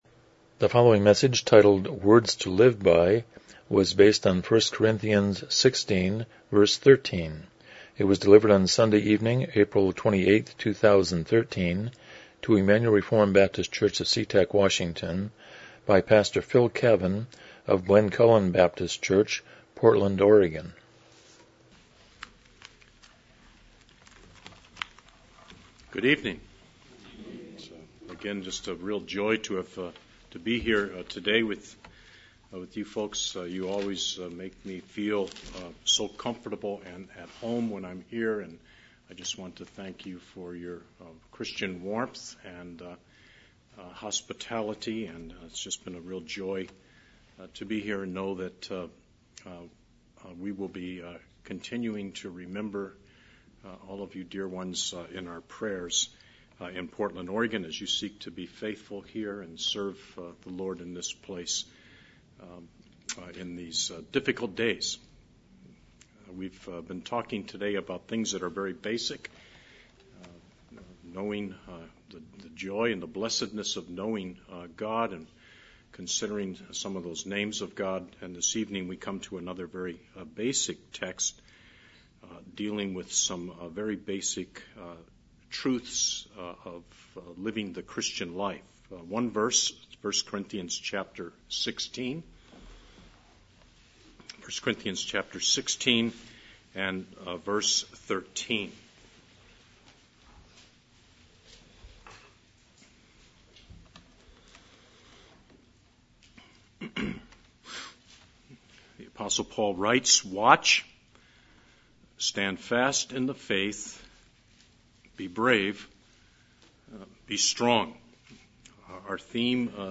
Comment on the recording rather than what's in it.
Miscellaneous Passage: 1 Corinthians 16:13 Service Type: Evening Worship « The Comfort of Knowing God 2013 Couples Retreat 1